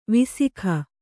♪ visikha